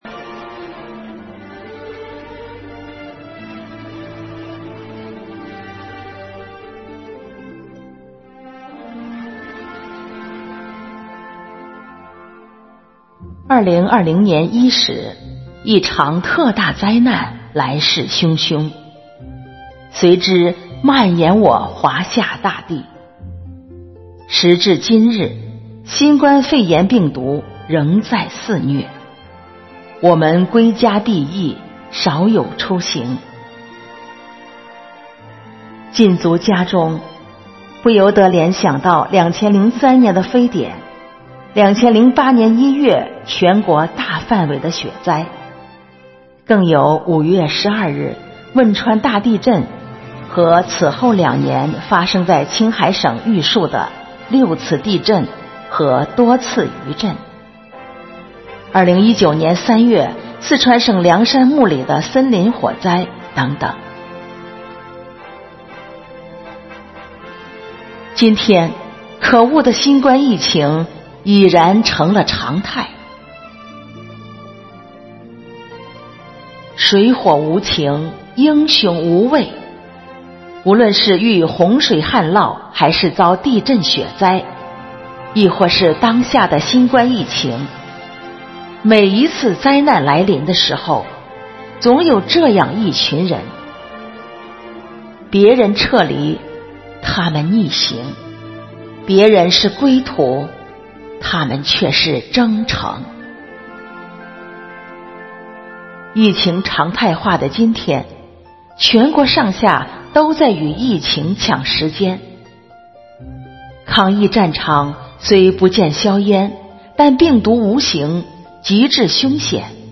暨中华诗韵支队第13场幸福志愿者朗诵会